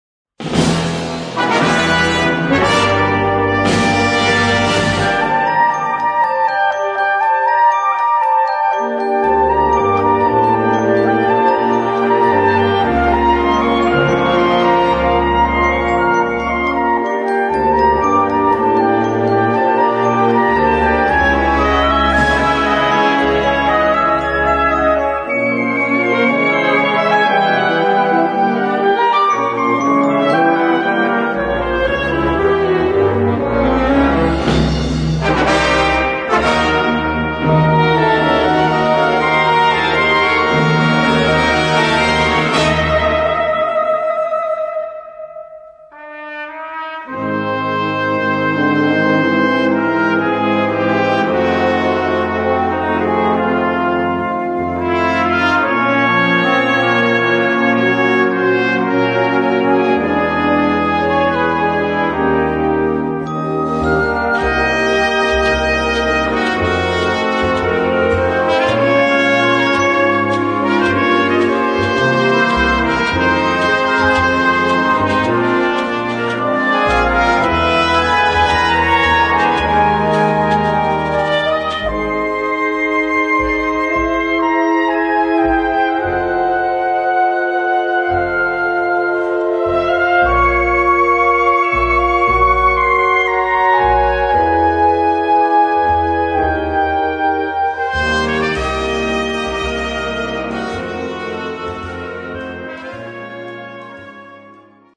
Noten für Blasorchester, oder Brass Band.